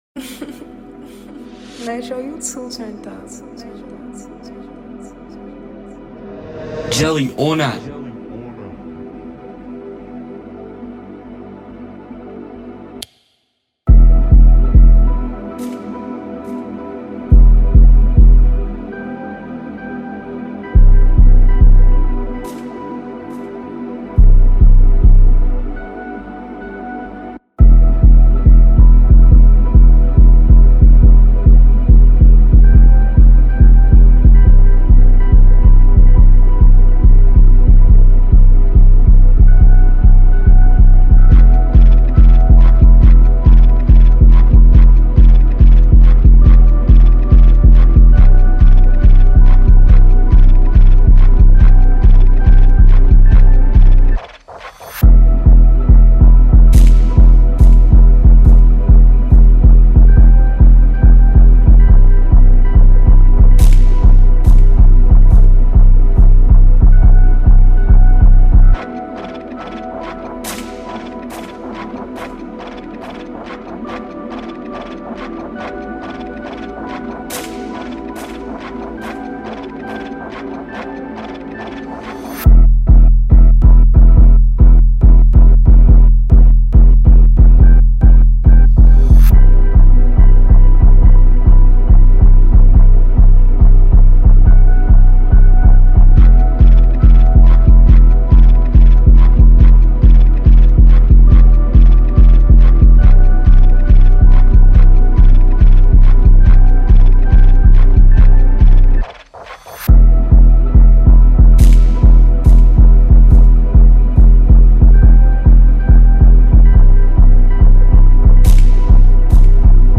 2024 in Jersey Drill Instrumentals